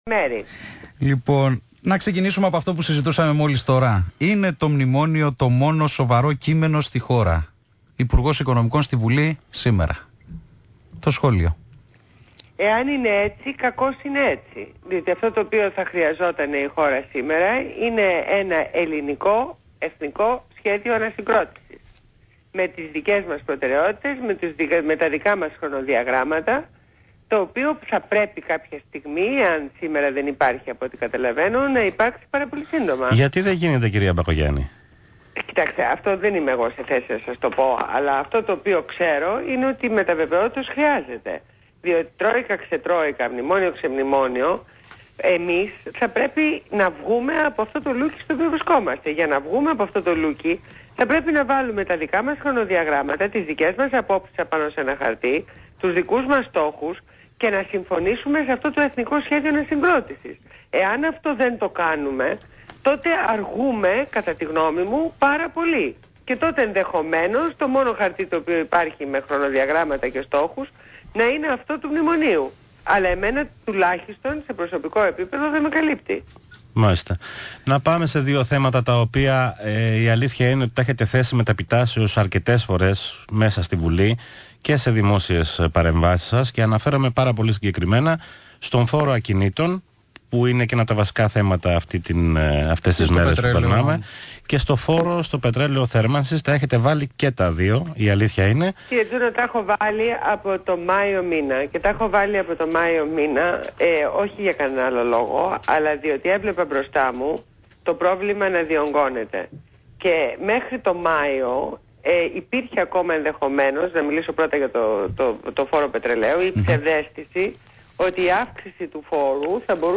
Συνέντευξη Ντόρας Μπακογιάννη στο ραδιόφωνο του ΣΚΑΙ